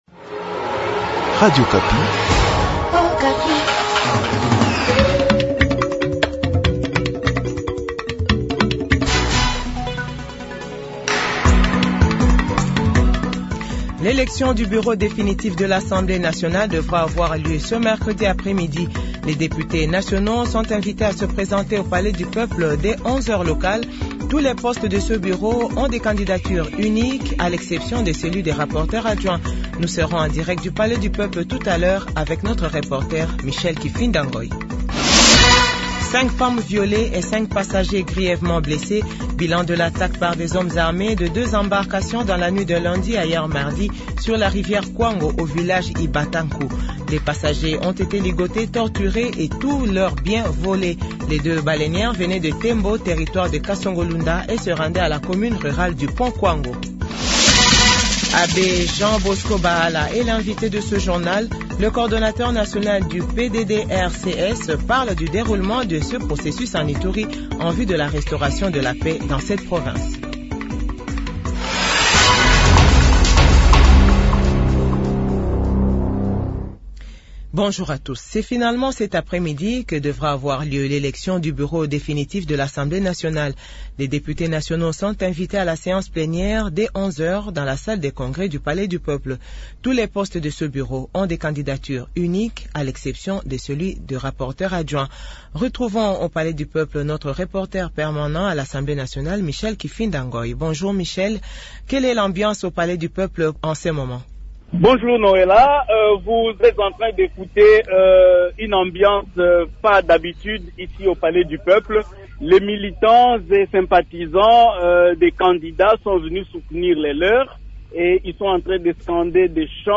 JOURNAL FRANCAIS 12H00